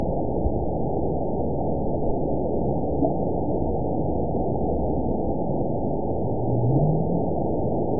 event 920818 date 04/10/24 time 07:58:45 GMT (1 year, 1 month ago) score 9.36 location TSS-AB02 detected by nrw target species NRW annotations +NRW Spectrogram: Frequency (kHz) vs. Time (s) audio not available .wav